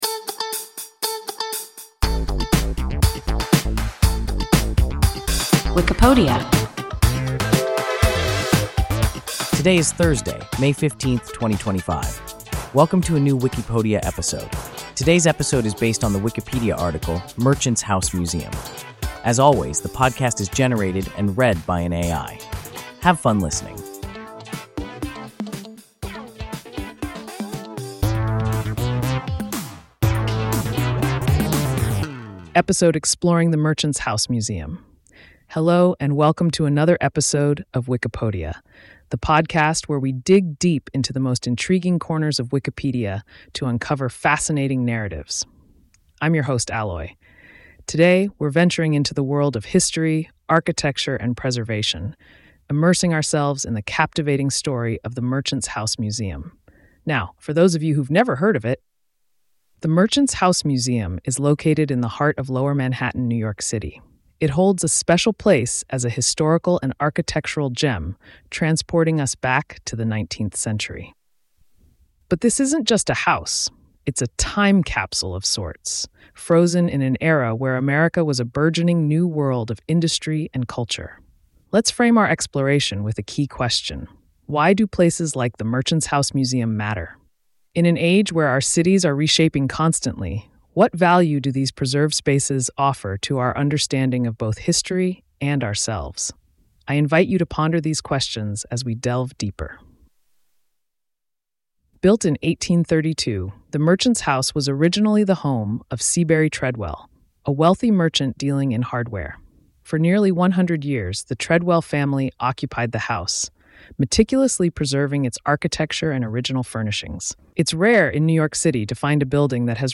Merchant’s House Museum – WIKIPODIA – ein KI Podcast